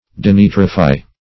Denitrify \De*ni"tri*fy\, v. t.